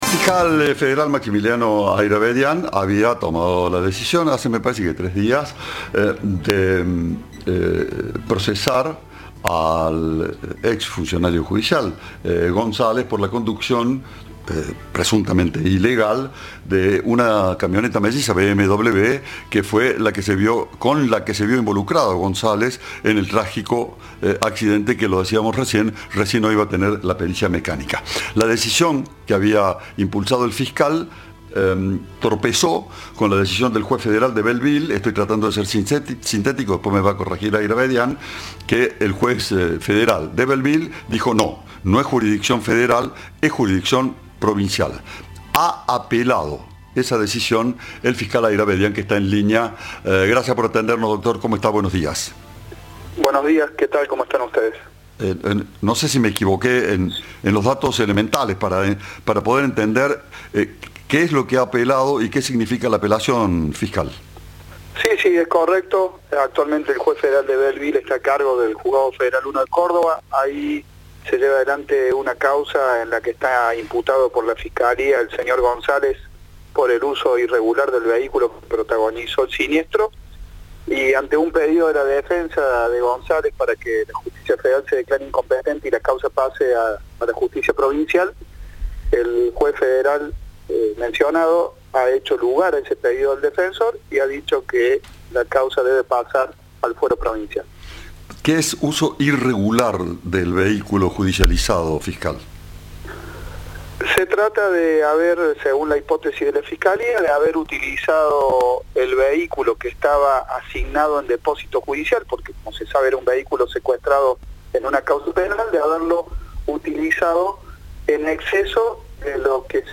El fiscal Hairabedian explicó a Cadena 3 por qué correspondería que la causa vaya a ese fuero.
Informe